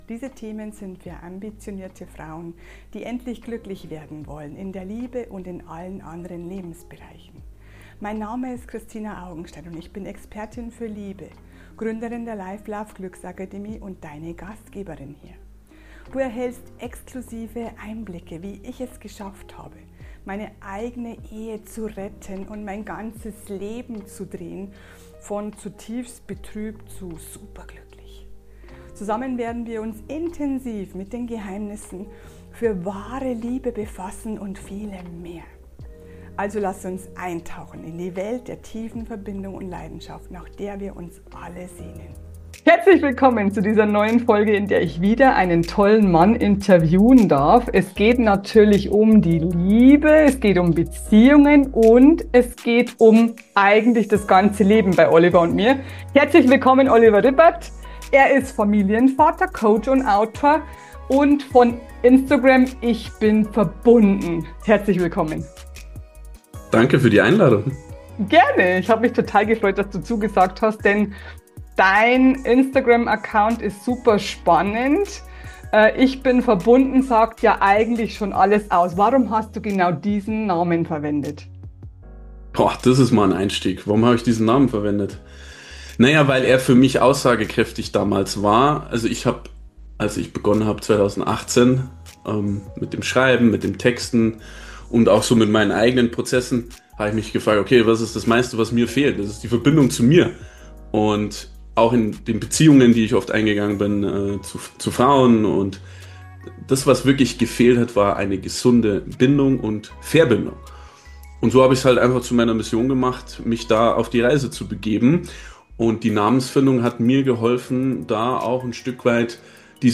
Die Frau ist der Chef – Interview